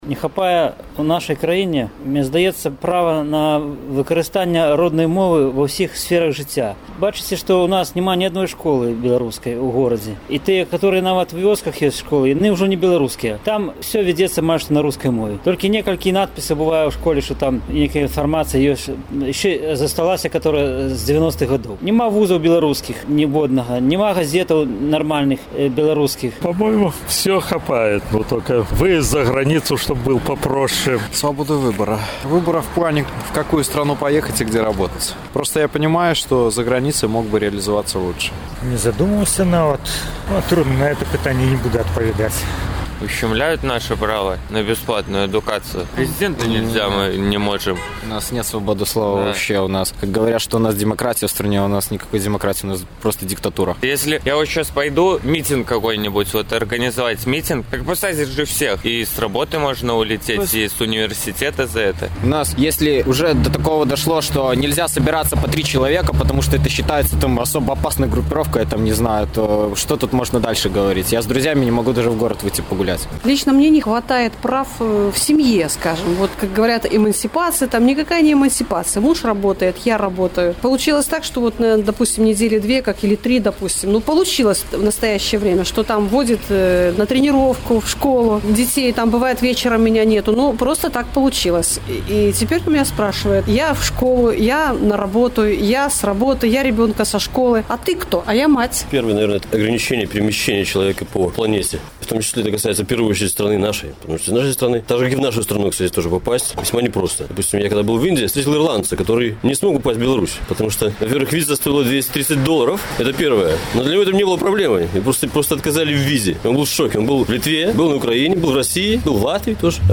Апытаньне ў Горадні: Якіх правоў вам не хапае болей за ўсё?